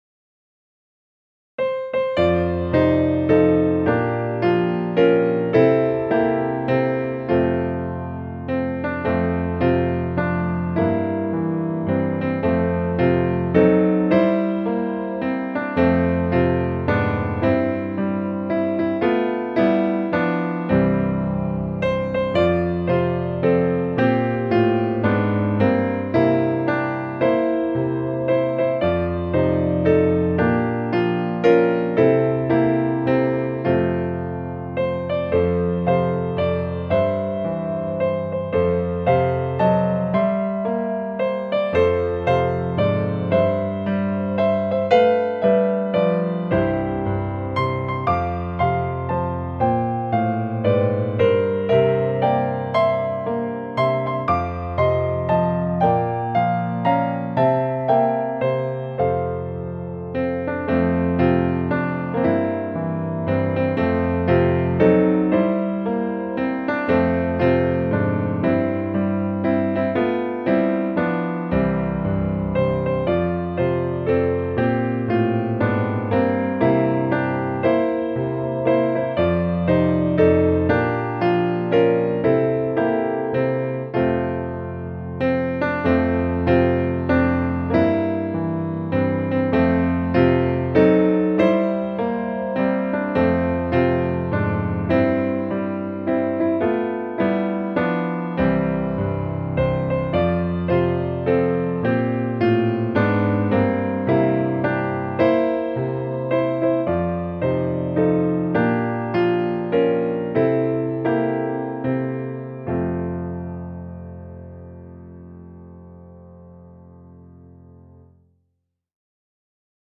This is a popular Christmas song.